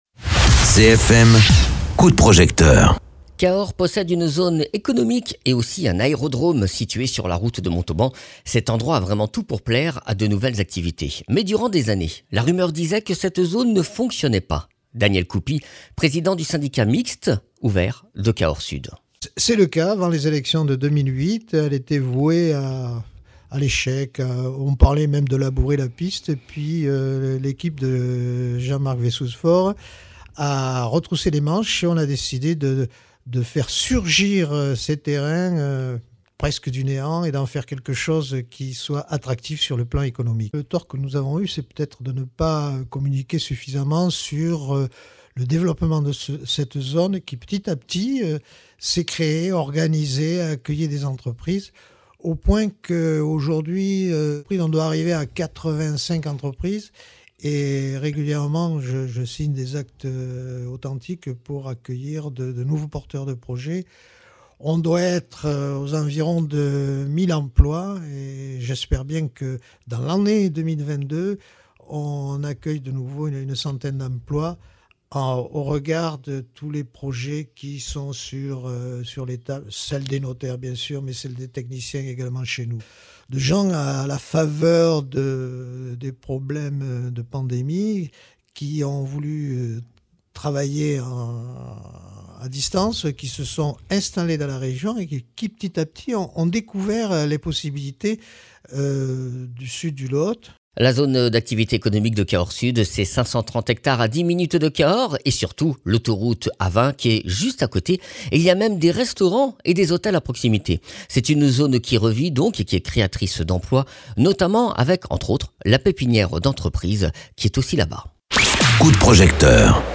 Interviews
Invité(s) : Daniel Coupy, Président du syndicat mixte ouvert de Cahors Sud